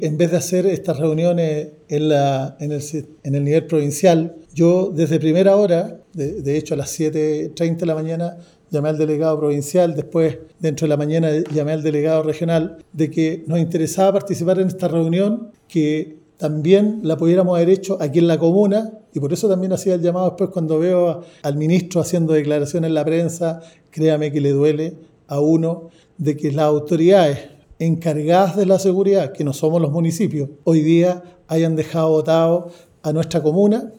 En conversación con Radio Bío Bío, el jefe comunal manifestó su preocupación por el daño a la imagen de la comuna y la confianza en los emprendedores e inversionistas.